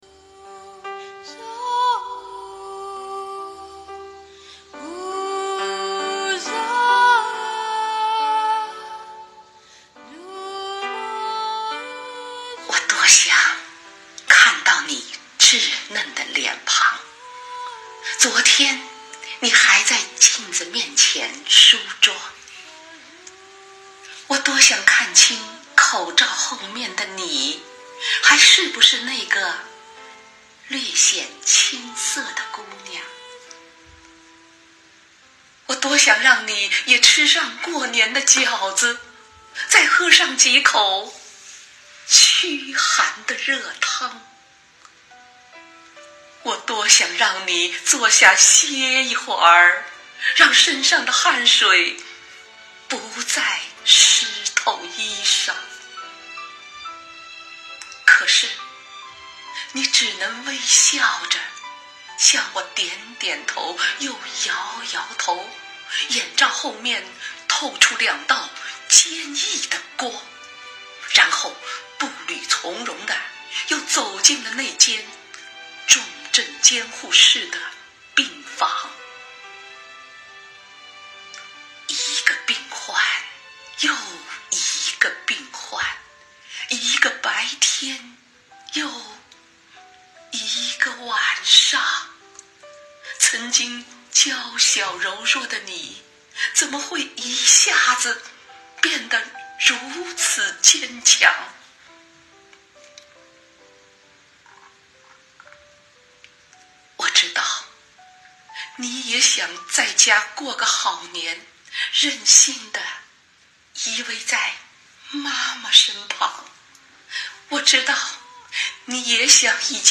《天使的脸庞》——北京科技大学教师深情为前线医护人员颂歌